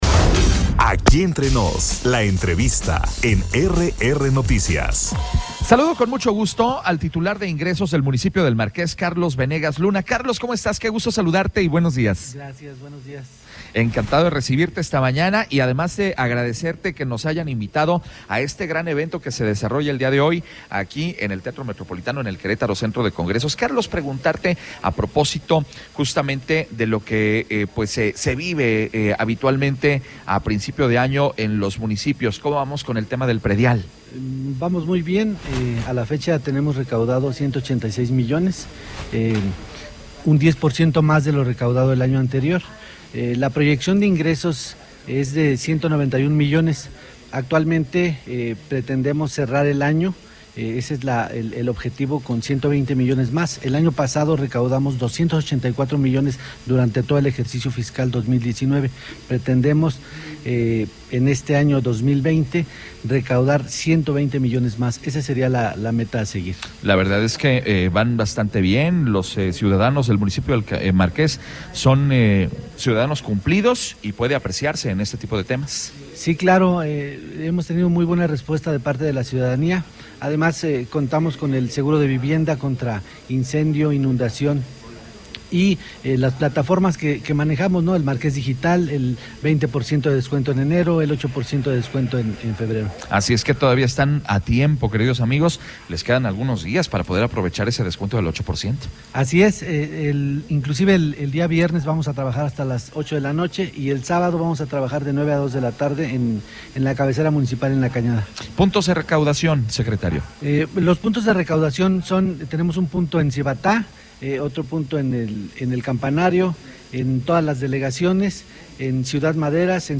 ENTREVISTA-1.mp3